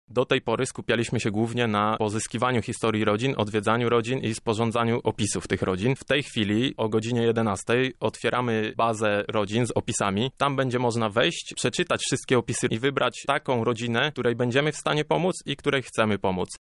wolontariusz, asystent do spraw promocji.